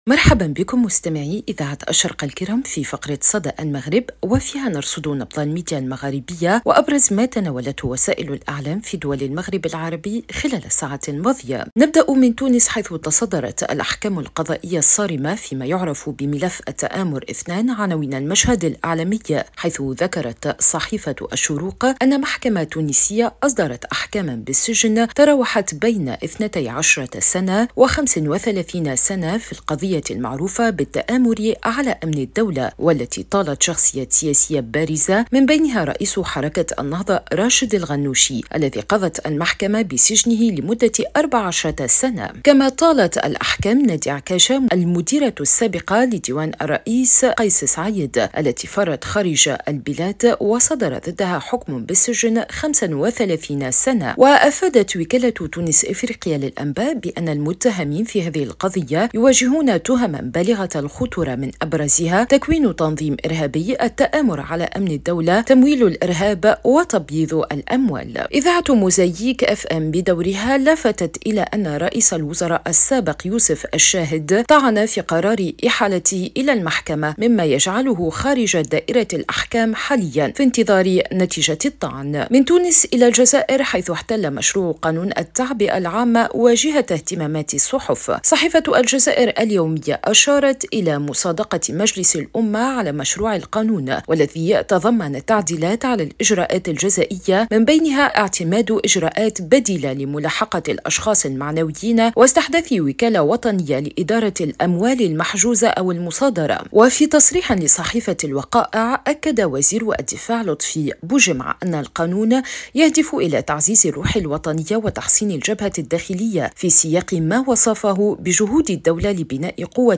صدى المغرب برنامج إذاعي يومي يُبث عبر إذاعة الشرق، يسلّط الضوء على أبرز ما تناولته وسائل الإعلام في دول المغرب العربي، بما في ذلك الصحف، القنوات التلفزية، والميديا الرقمية.